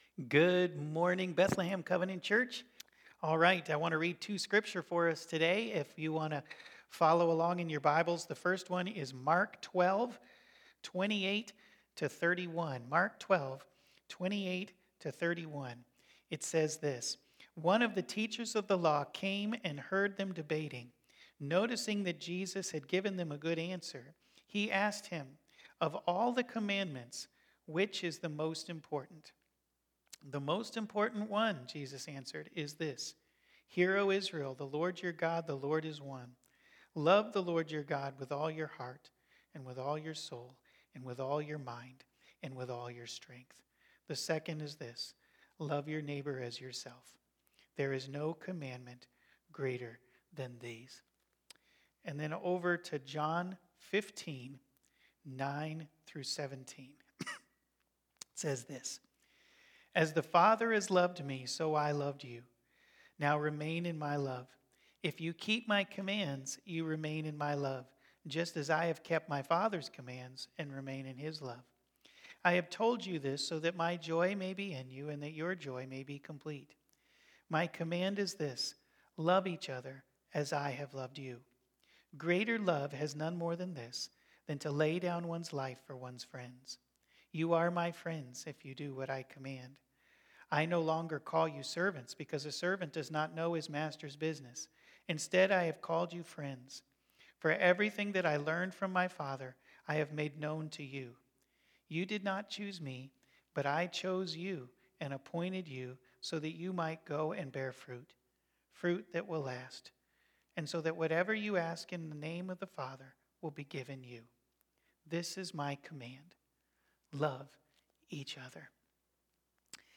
Bethlehem Covenant Church Sermons The Truth about Love Jan 20 2022 | 00:31:55 1x 00:00 / 00:31:55 Subscribe Share Spotify RSS Feed Share Link Embed
sermon-love-FINAL.mp3